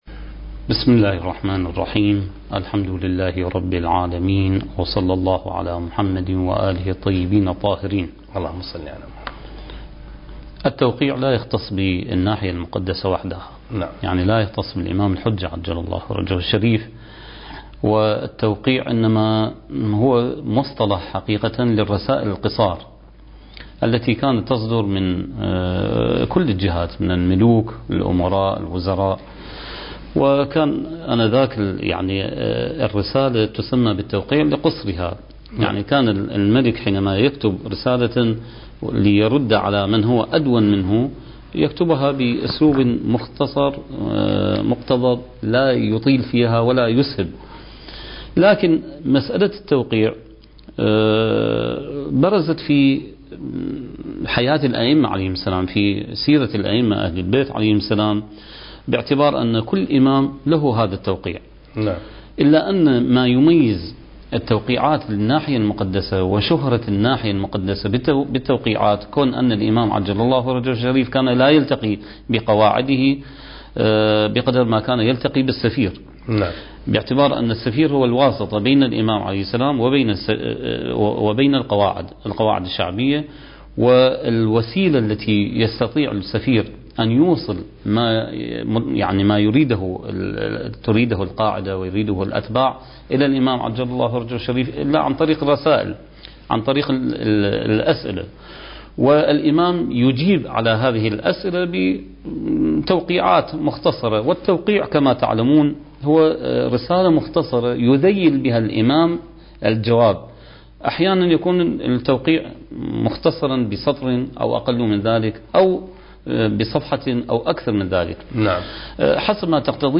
سلسلة محاضرات: بداية الغيبة الصغرى (4) برنامج المهدي وعد الله انتاج: قناة كربلاء الفضائية